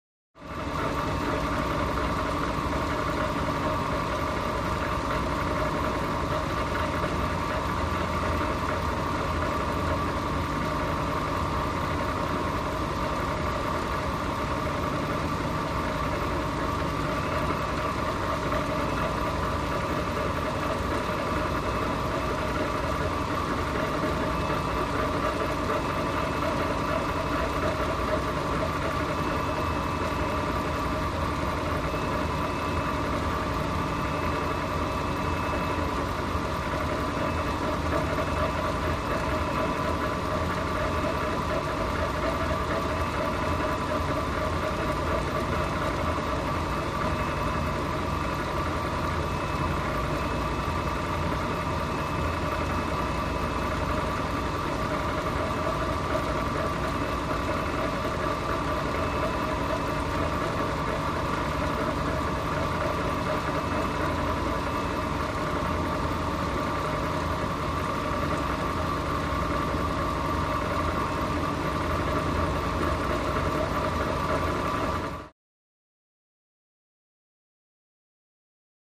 Industrial Machinery - Sewage Reclamation Plant